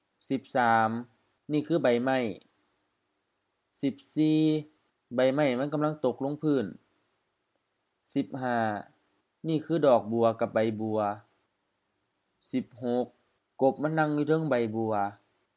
Notes: pronunciation: also realized as ทัง